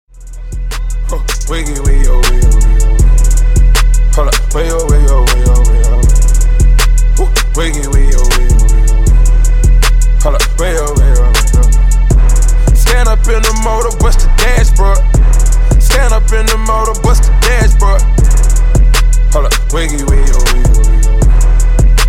• Качество: 320, Stereo
мужской вокал
Хип-хоп
club
Rap